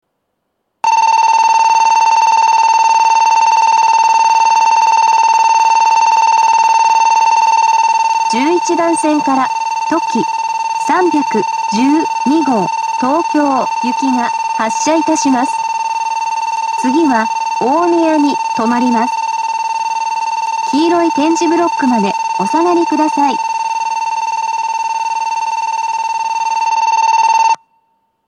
２面４線のホームで、全ホームで同じ発車ベルが流れます。
２０２１年９月１２日にはCOSMOS連動の放送が更新され、HOYA製の合成音声による放送になっています。
１１番線発車ベル とき３１２号東京行の放送です。